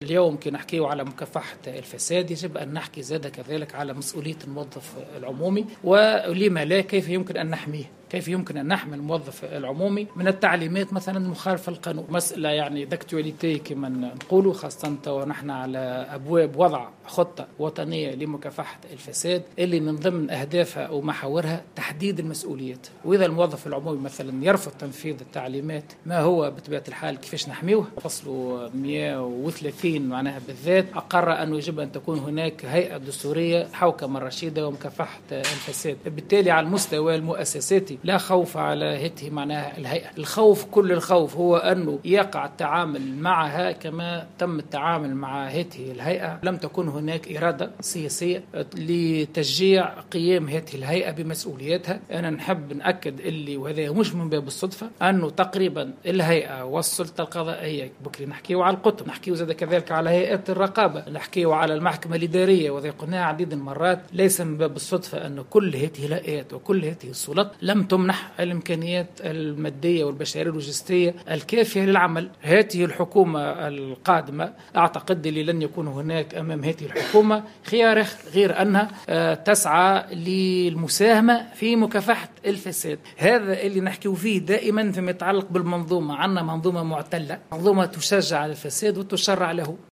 أكد رئيس الهيئة الوطنية لمكافحة الفساد شوقي الطبيب خلال دورة تدريبية حول آليات مكافحة الفساد انتظمت اليوم الثلاثاء في العاصمة، أنه لن يكون أمام الحكومة القادمة من خيار سوى المضي القدم في مكافحة الفساد والمساهمة في ذلك، بعد أن تعهد رئيسها يوسف الشاهد بذلك في عقب أول تصريح له بعد تكليفه.
وبين الطبيب في تصريح لمراسل الجوهرة أف أم، أن مساهمة الحكومة في هذا المجال تكون من خلال منح الهيئات والسلط المختصة في مكافحة الفساد الإمكانيات اللازمة للعمل، ومن خلال تدعيم الإطار التشريعي، واصفا المنظومة القانونية التونسية بـ"المعتلة والتي تشجع الفساد".